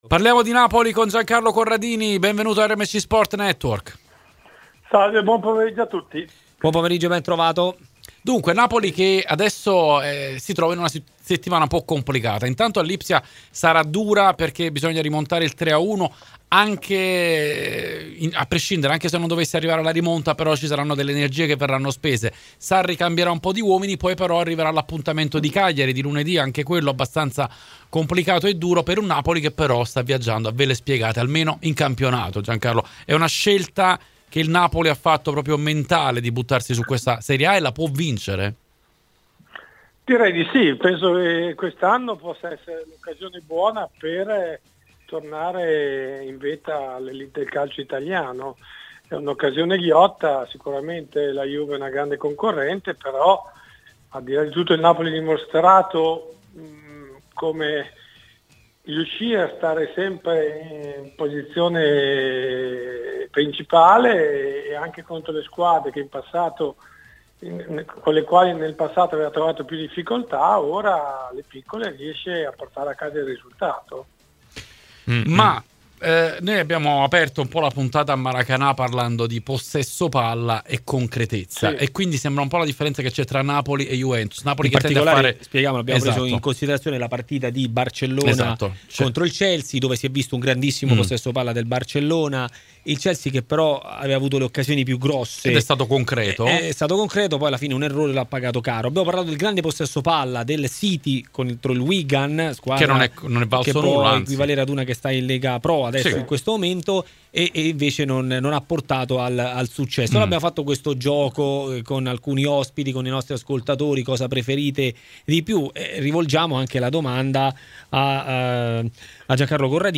Altro ospite nel pomeriggio di RMC Sport.
intervistato